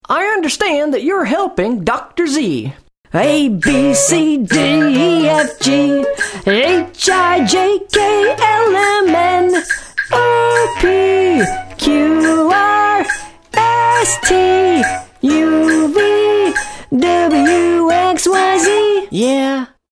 Project: Voices and music for online reading program